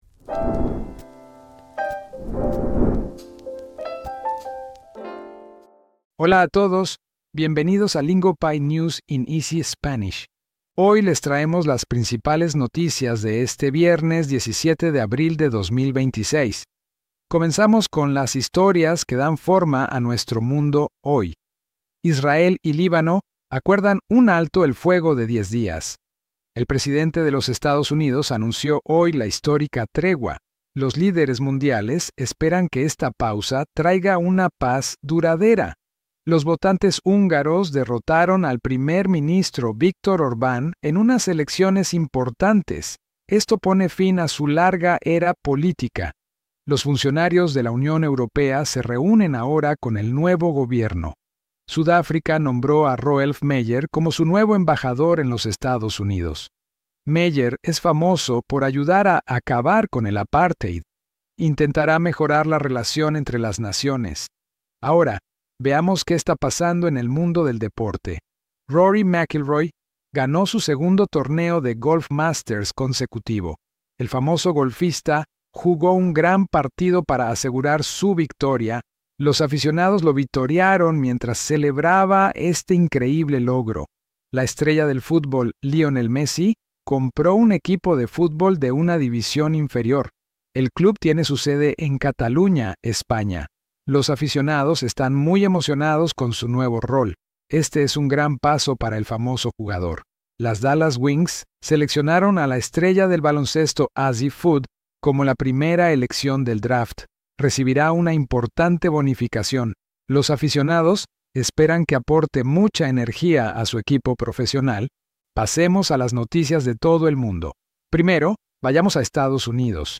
This is News in Easy Spanish, Lingopie’s slow Spanish news podcast built for real Spanish listening practice. We take today’s biggest global headlines and present them in clear, beginner-friendly Spanish so you can actually follow along and improve your listening without feeling lost.